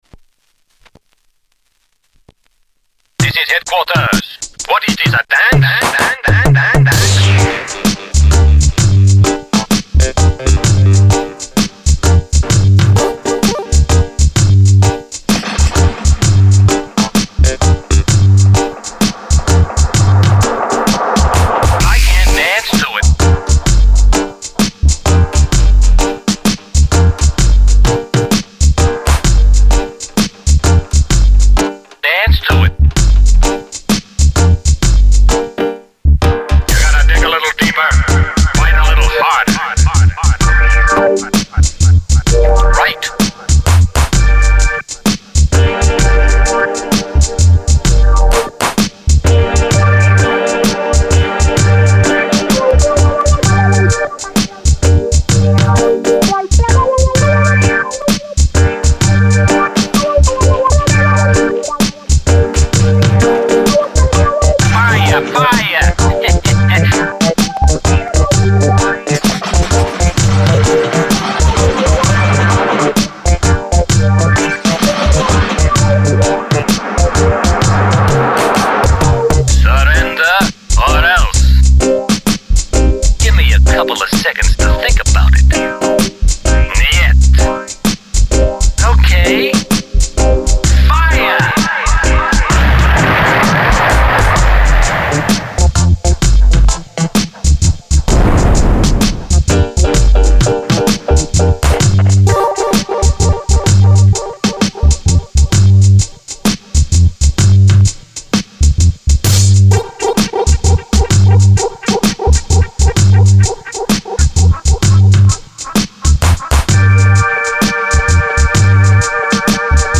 extremely smoked out jam session
Watch that tape hiss on your intercom!